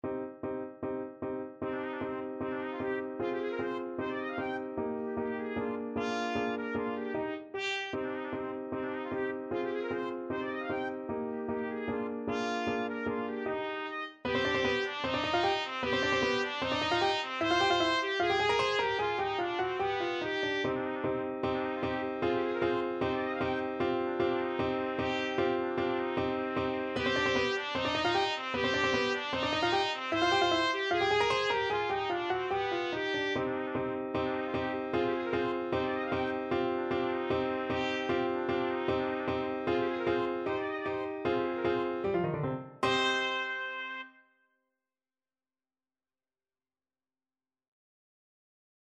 Trumpet
2/4 (View more 2/4 Music)
C minor (Sounding Pitch) D minor (Trumpet in Bb) (View more C minor Music for Trumpet )
Allegro scherzando (=152) (View more music marked Allegro)
Classical (View more Classical Trumpet Music)